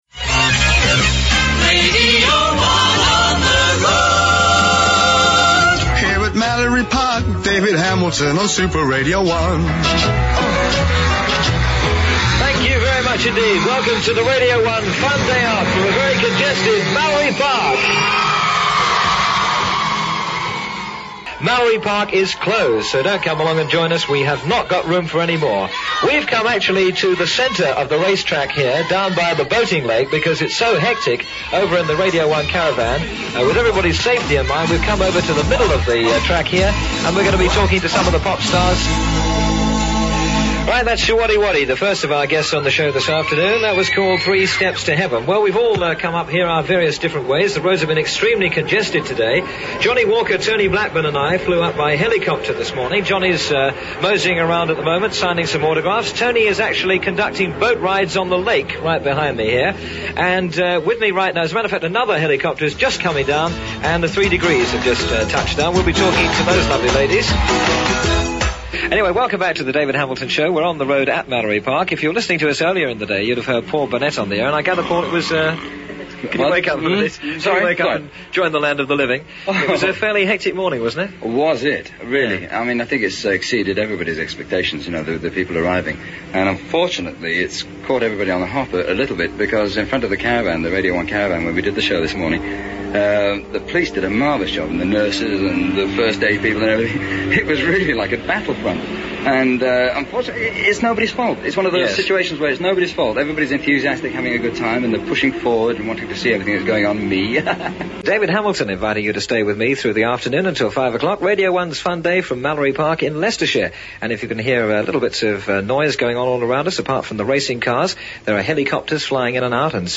Radio One also created a range of Fun Days, with one particularly memorable one being held in May 1975 in Leicestershire.